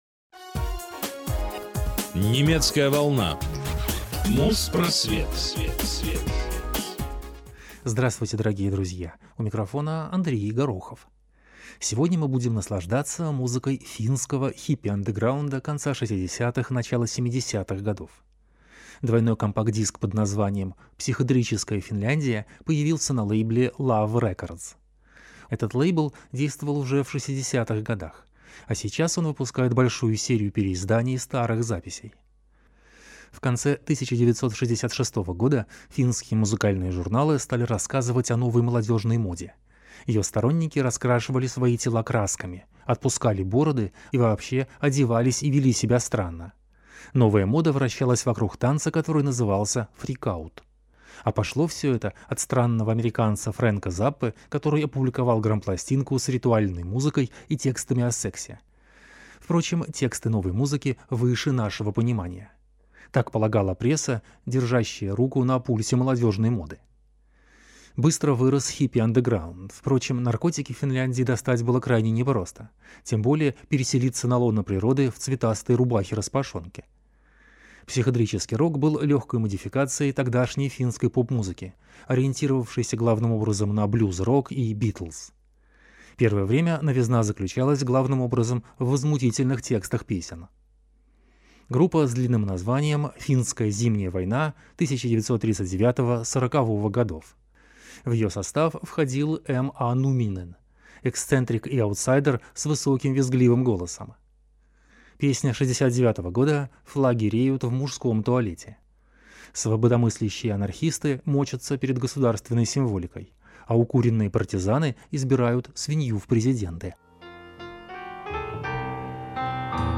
Финская психоделика Psychedelic Phinland.